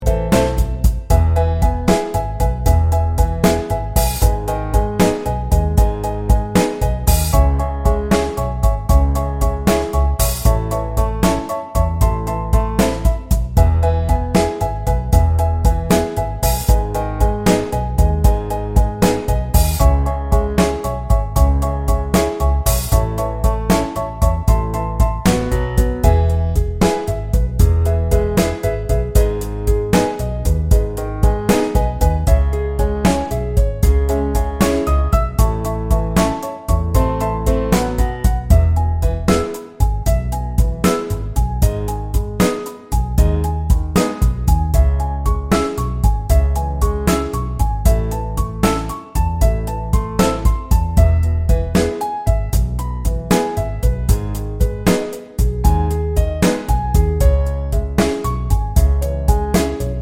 no Backing Vocals Jazz / Swing 4:00 Buy £1.50